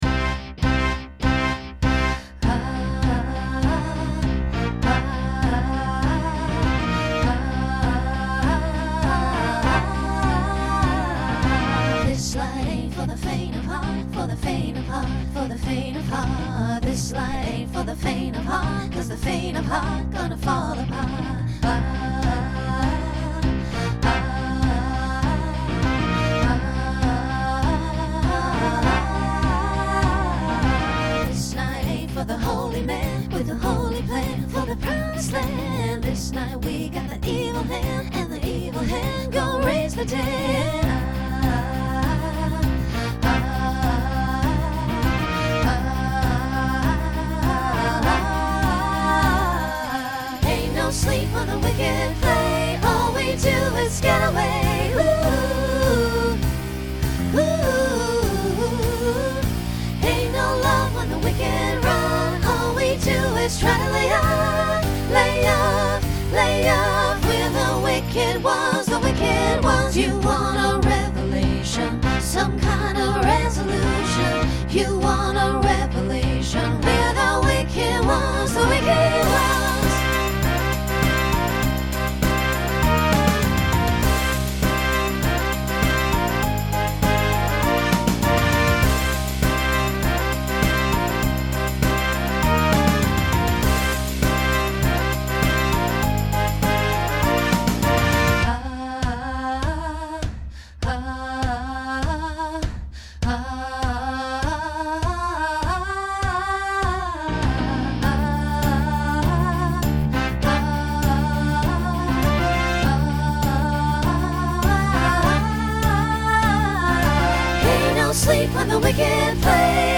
Genre Rock Instrumental combo
Transition Voicing SSA